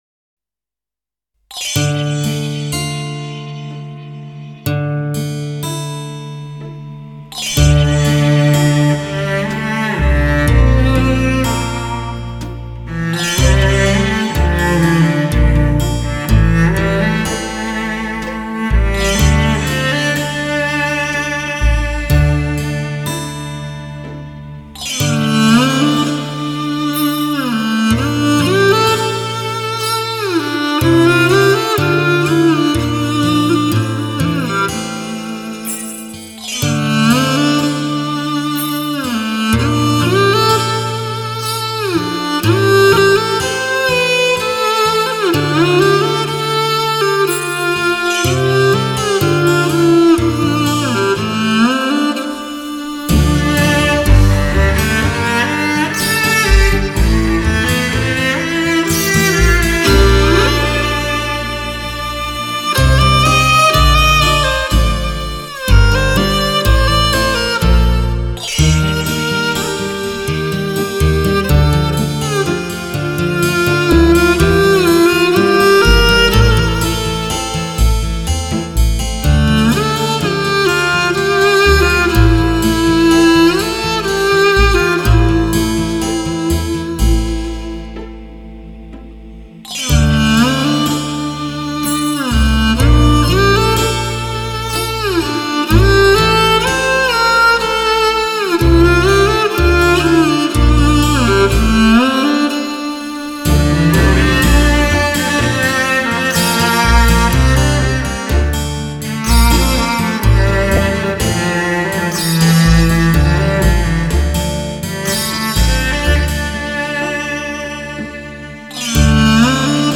2周前 纯音乐 5